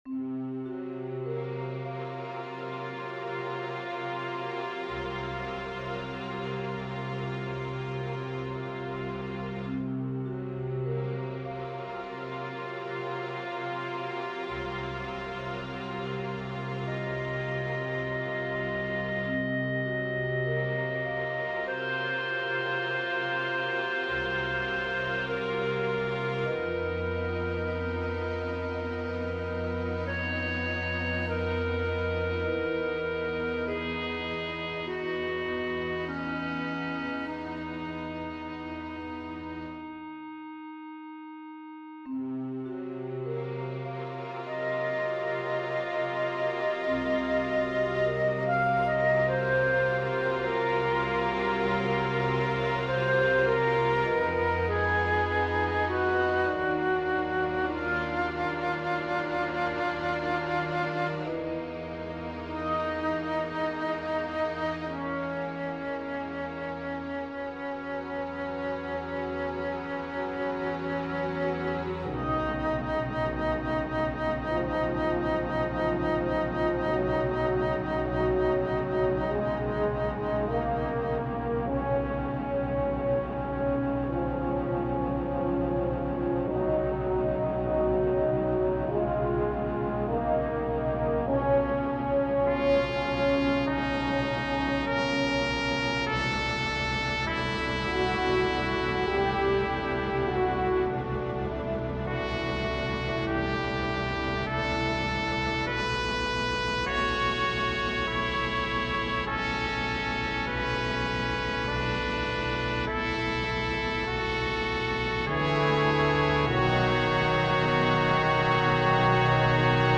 A week or so ago, my orchestra piece "Nightfall" was chosen as the winner of Parma Recordings Summer Call For Scores!